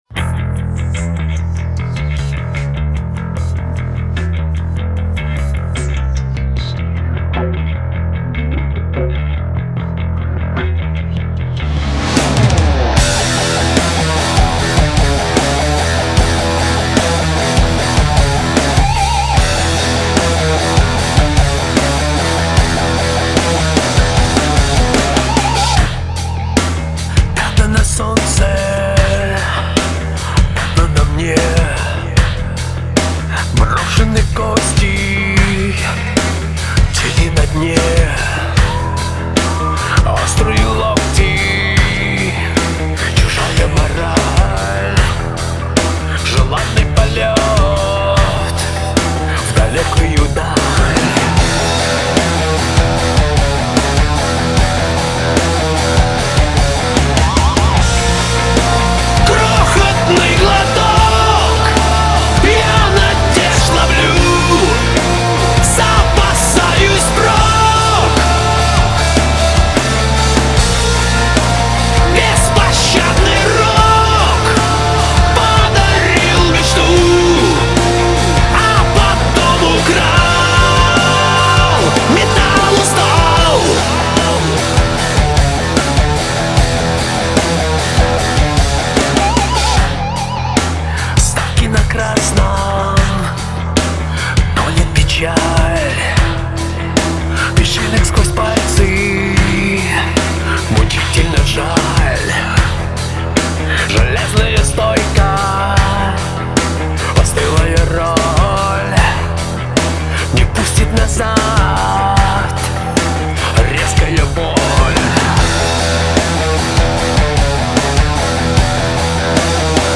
Категория: Rock 2016